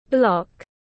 Hình khối tiếng anh gọi là block, phiên âm tiếng anh đọc là /blɒk/